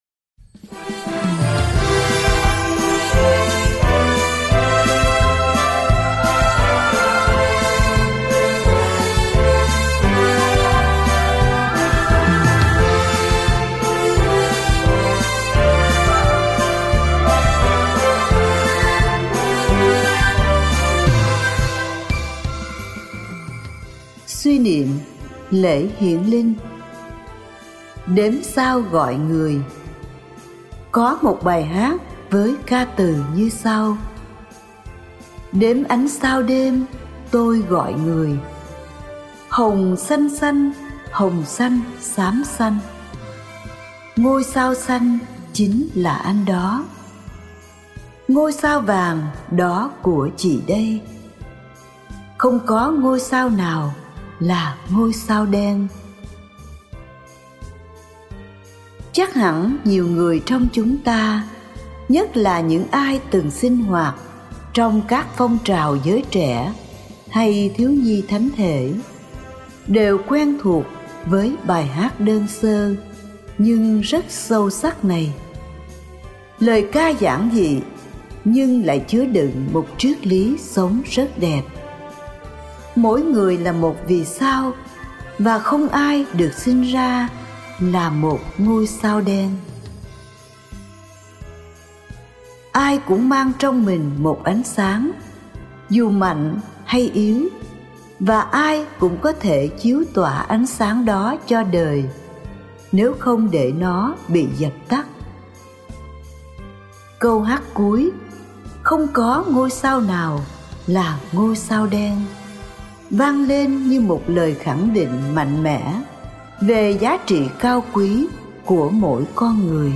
Đếm sao gọi người (Bài giảng lễ Chúa Hiển Linh - 2025)